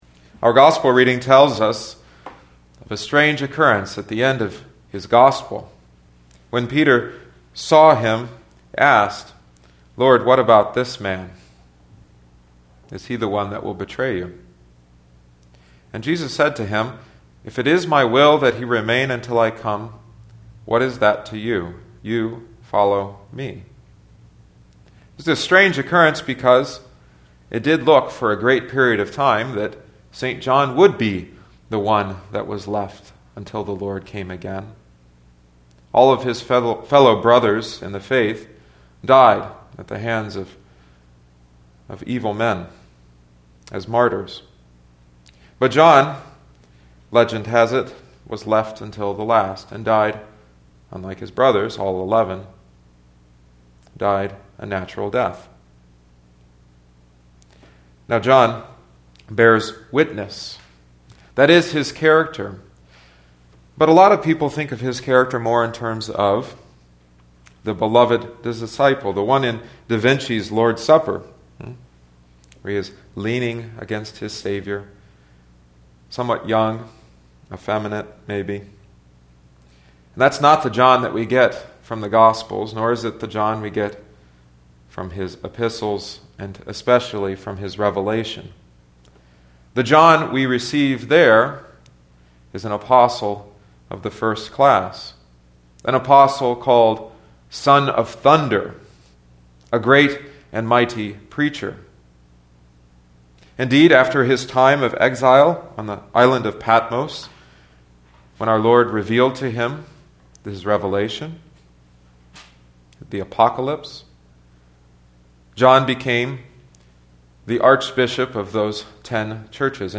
(Audio only sermon)